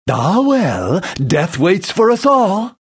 Obľuboval som chaotického ninju, pred začiatkom tuhého boja
Hlášok je nepreberné množstvo a sú fakt kvalitne nadabované, intonácia a výber hlasov sedia presne do situácií, kedy postava povie svoju repliku.